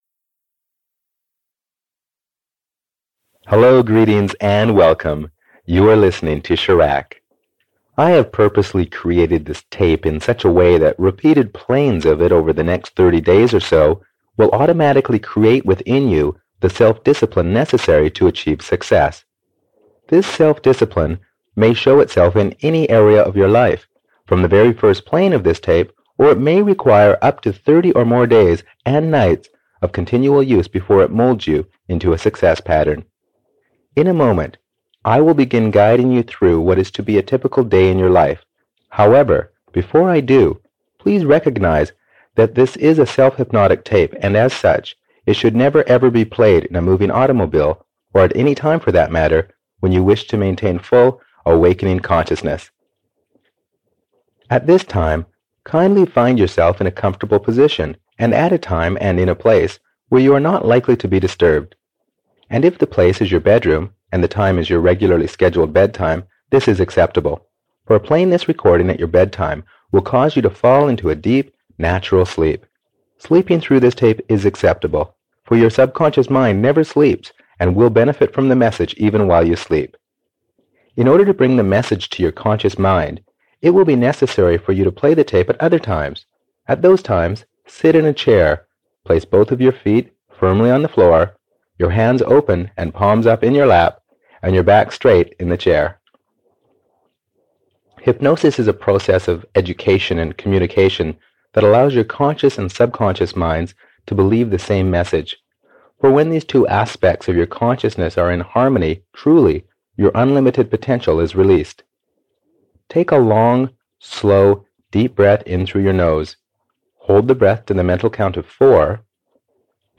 Money and Prosperity 1 (EN) audiokniha
Ukázka z knihy